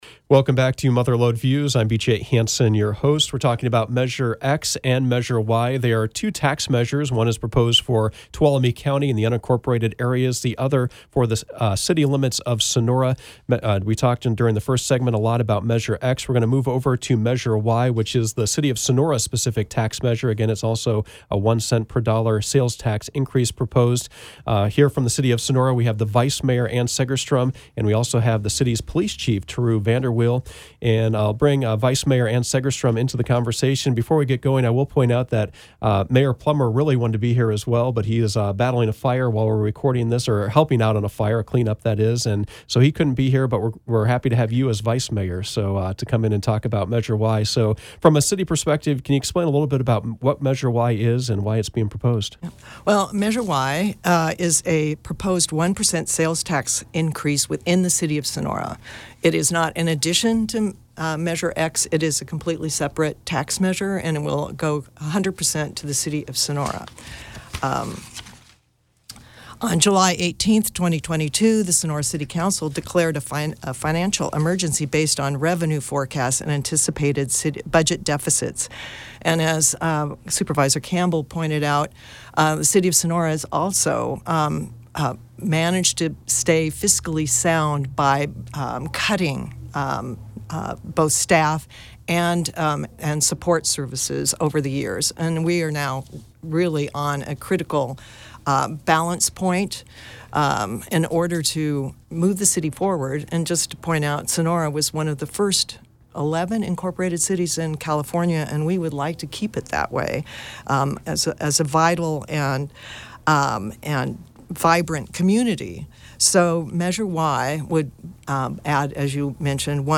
Mother Lode Views focused on the one-cent sales tax measures X and Y that are on the November 8 ballot. Measure X is for the unincorporated parts of Tuolumne County and Measure Y is for the City of Sonora. The guests were Tuolumne County Supervisor Ryan Campbell, Tuolumne County Sheriff Bill Pooley, Sonora Police Chief Turu VanderWiel and Sonora Vice Mayor Ann Segerstrom.